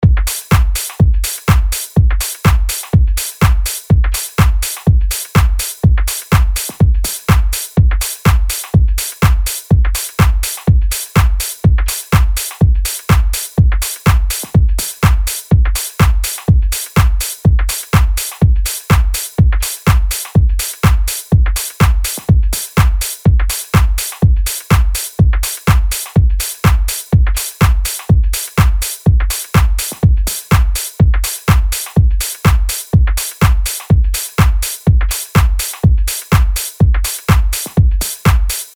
LP 128 – DRUM LOOP – EDM – 124BPM